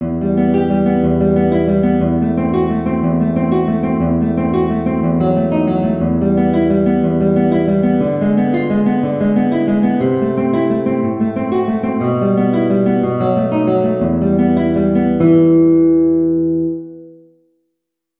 E minor (Sounding Pitch) (View more E minor Music for Guitar )
6/8 (View more 6/8 Music)
E3-G5
Guitar  (View more Easy Guitar Music)
Classical (View more Classical Guitar Music)
Prelude_In_Em_Caracssi_GTR.mp3